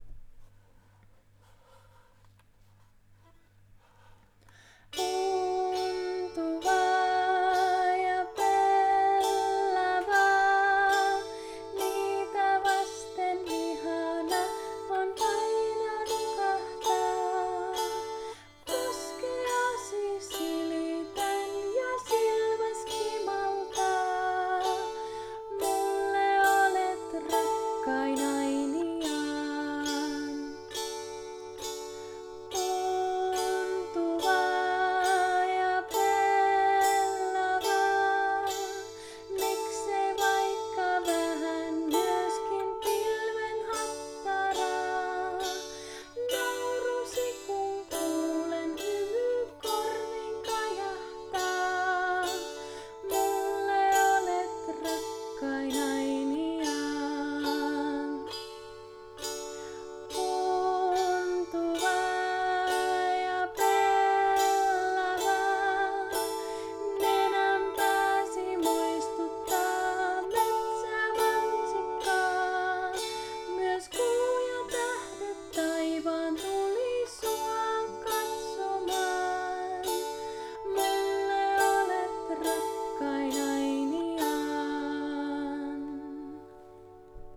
Olen äänittänyt lauluni laulaen ja soittaen joko 5-kielisellä kanteleella tai pianolla. Säveltämäni kappaleet ovat sävelletty myötäillen suomalaista kansanmusiikkiperinnettä, ja niissä on vivahteita länsimaalaisesta populaarimusiikista.